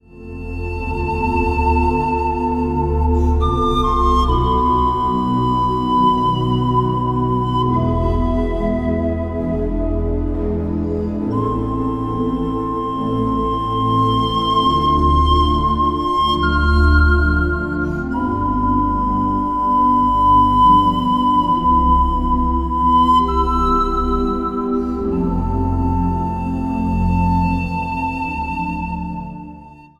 Instrumentaal | Panfluit
Reformatorisch Dagblad | Panfluiten en orgel
Panfluit en orgel: een beproefd concept.
Het zijn vrolijke muziekjes waar het plezier vanaf spat.